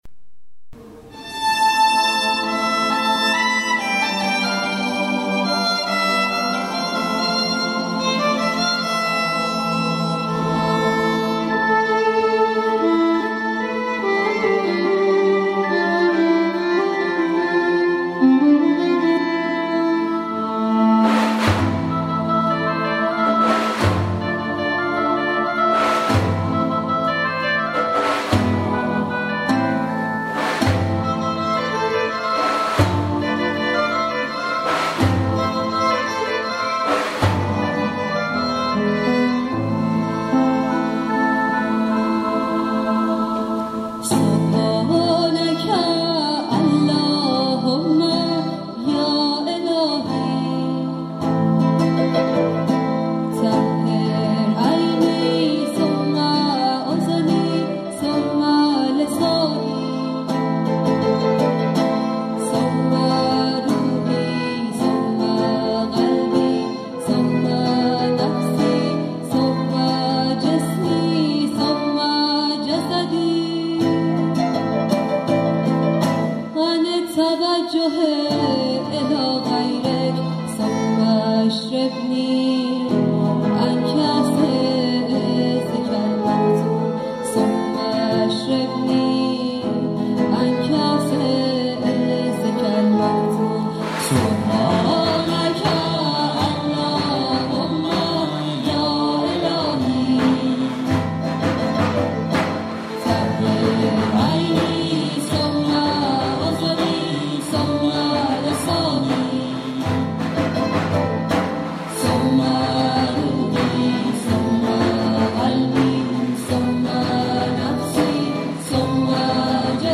الله ابهی موسیقی خیلی قشنگی بود من که واقعا خوشم اومد.